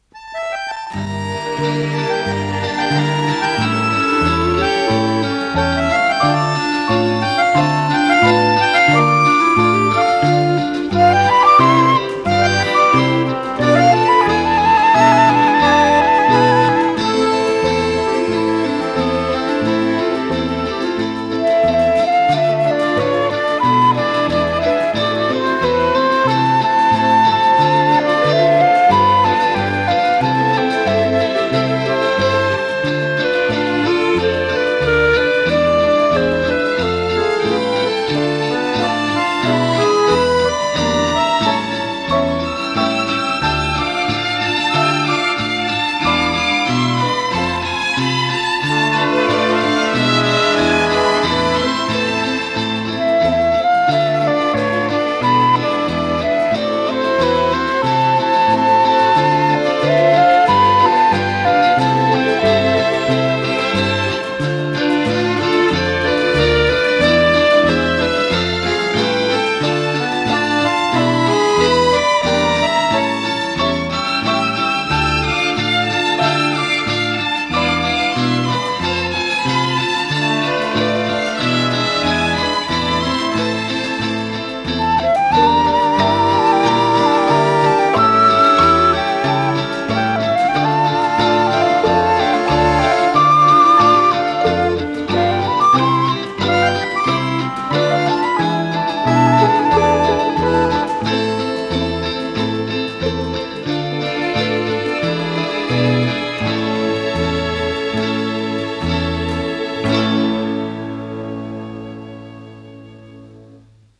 ПодзаголовокЗаставка, ре минор
ВариантДубль моно